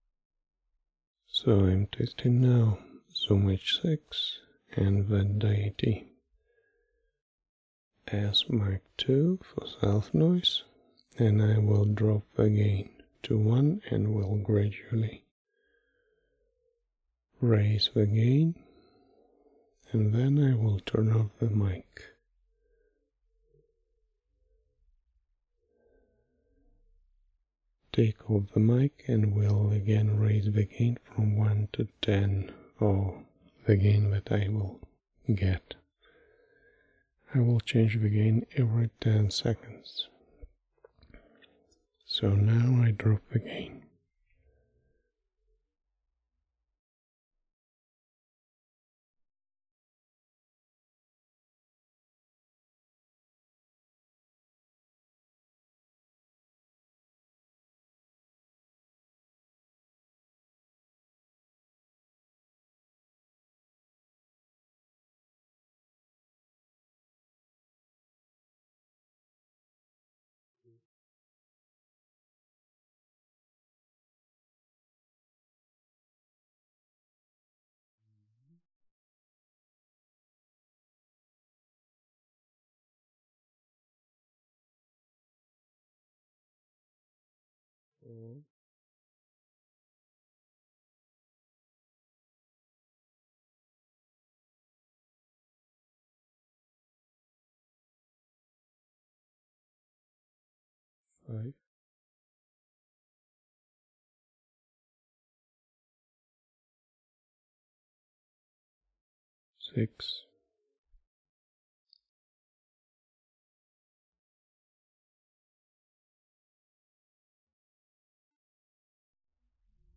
I processed this in Reaper using the REAFir plug-in. Does this sound quieter to you?